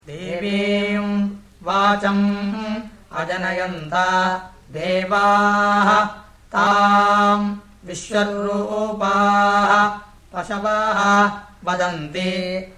Vikrti Recitation
Padapātha
00-veda2-vkrti-pada.mp3